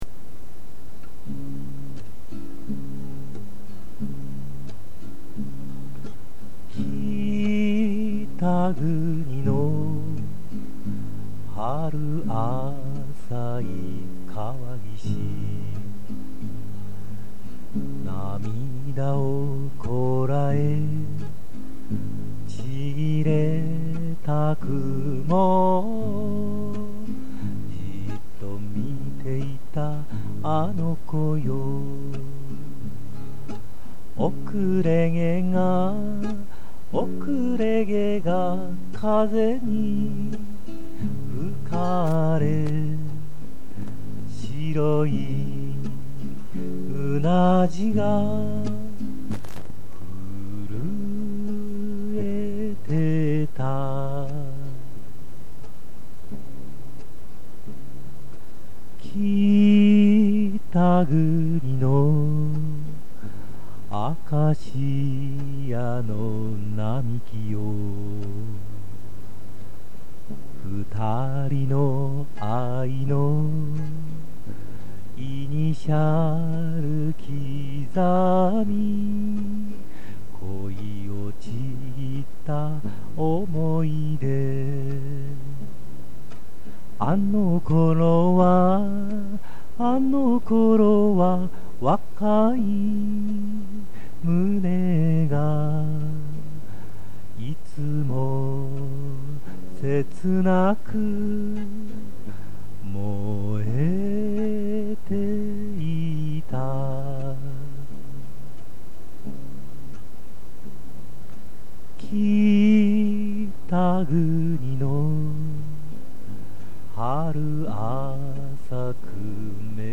多重録音ではなく、キーも下げているのでもうひとつですが、、、